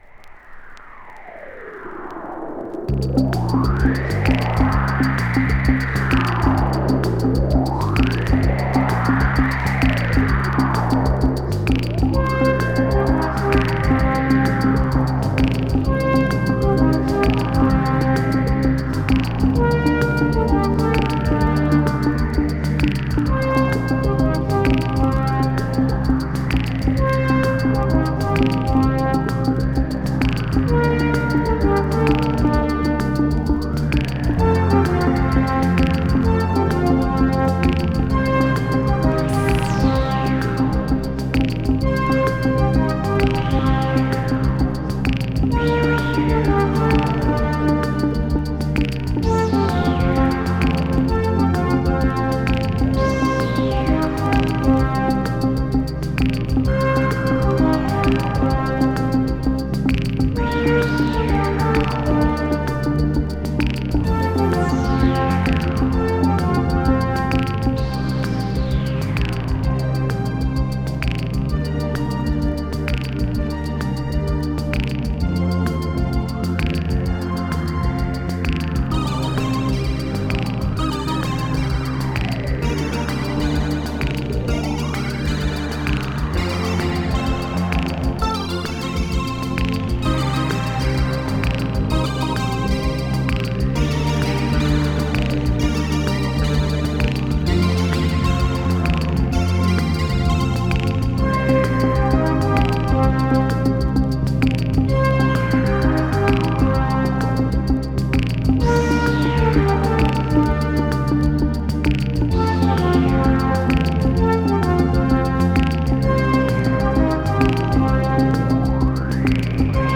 【DISCO】